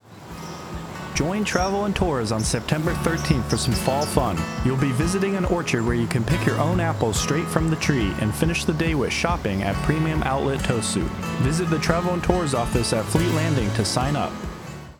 A radio promotion